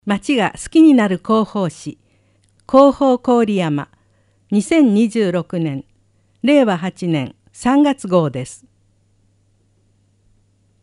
「声の広報」は、「広報こおりやま」の一部記事を「視覚障がい者支援ボランティアグループ　くるみ会」の皆さんが読み上げています。